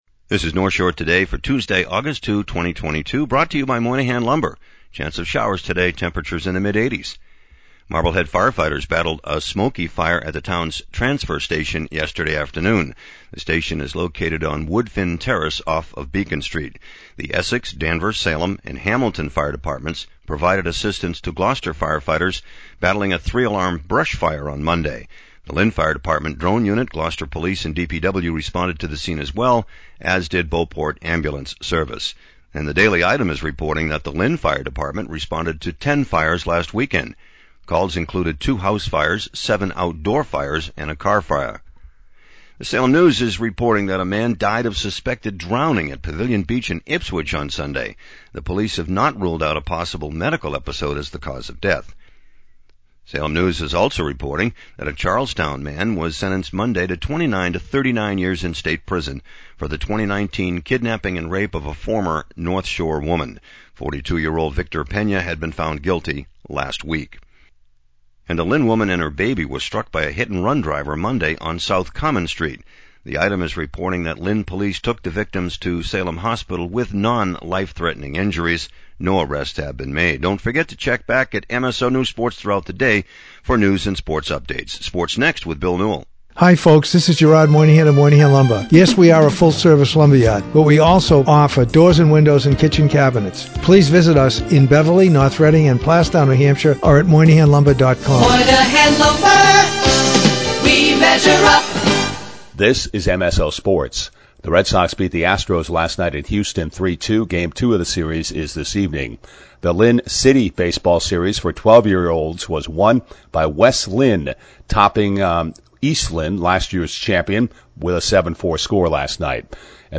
North Shore Today News & Sports Podcast